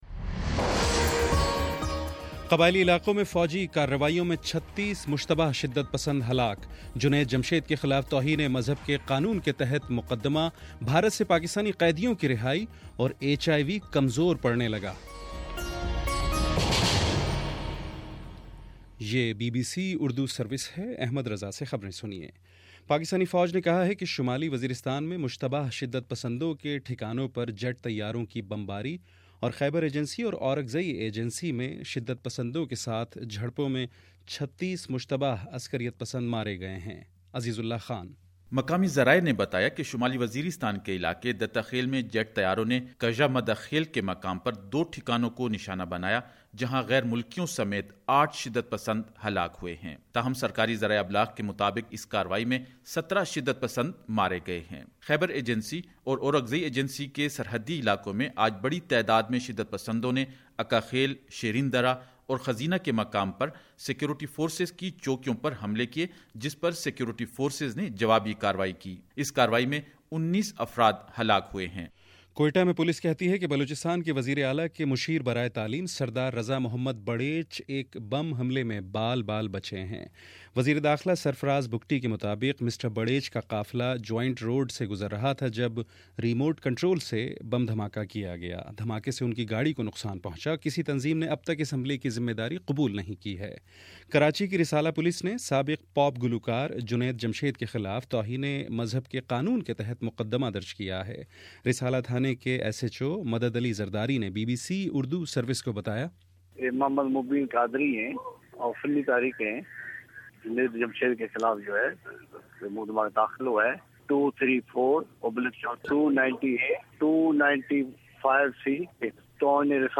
د سمبر 02: شام چھ بجے کا نیوز بُلیٹن